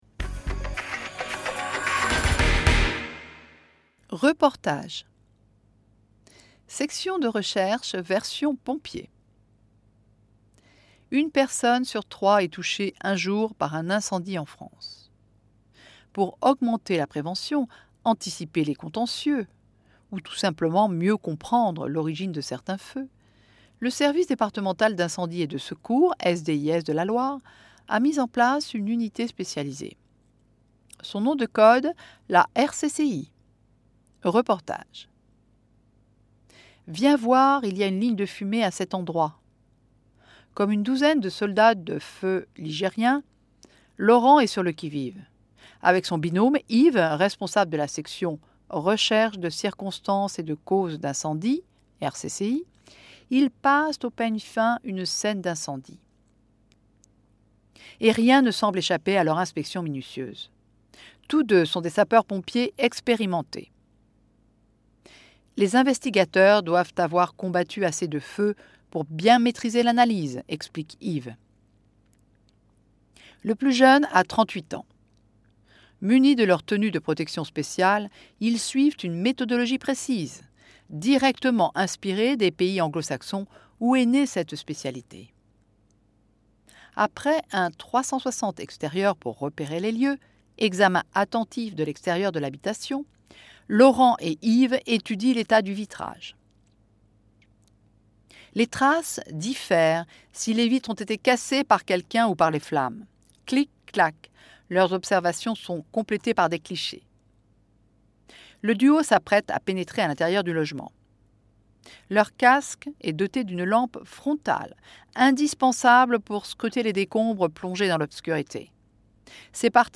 REPORTAGE
113-reportage.mp3